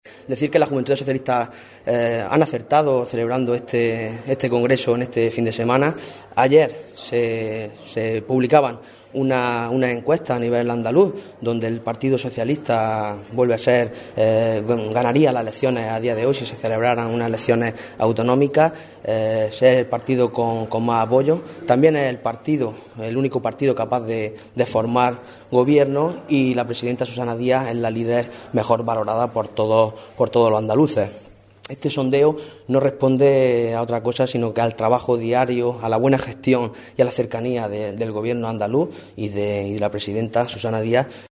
Congreso JSA Almería